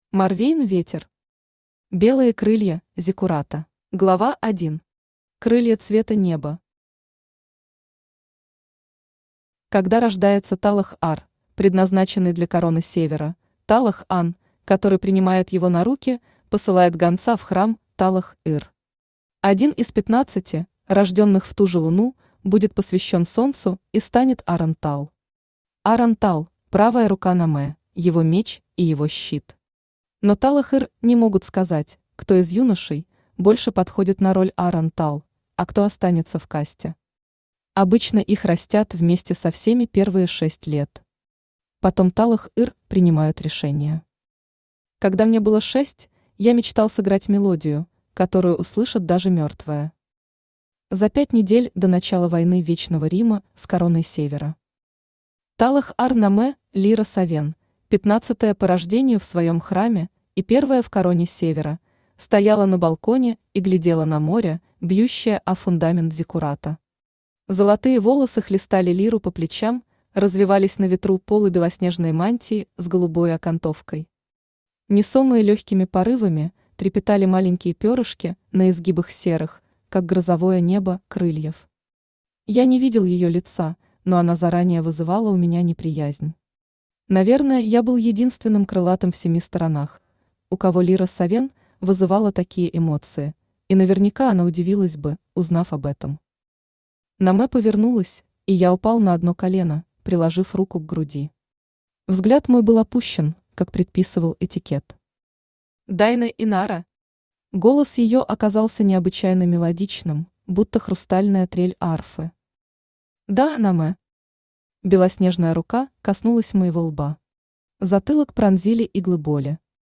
Аудиокнига Белые крылья зиккурата | Библиотека аудиокниг
Aудиокнига Белые крылья зиккурата Автор Морвейн Ветер Читает аудиокнигу Авточтец ЛитРес.